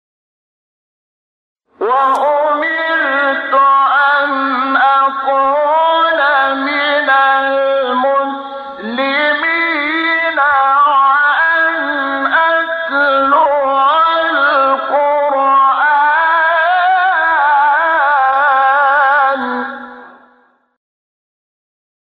سایت قرآن کلام نورانی - چهارگاه شحات انور (3).mp3
سایت-قرآن-کلام-نورانی-چهارگاه-شحات-انور-3.mp3